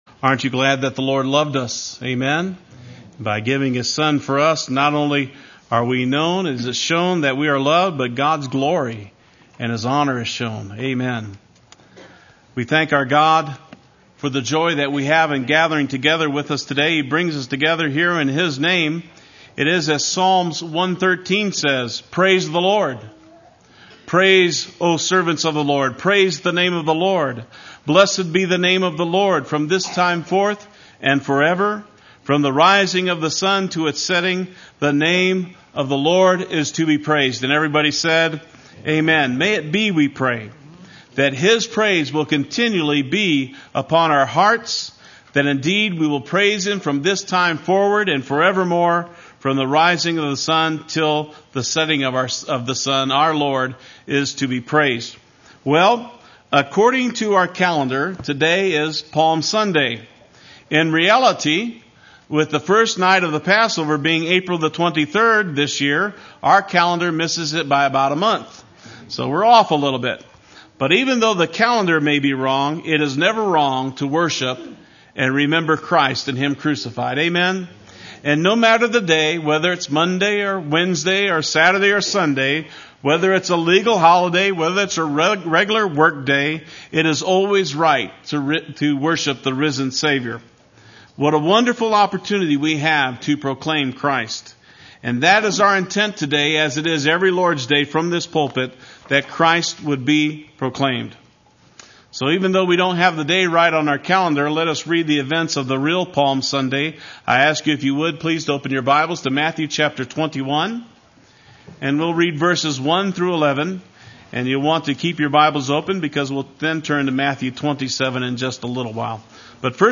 Play Sermon Get HCF Teaching Automatically.
Palm Sunday 2005 Sunday Worship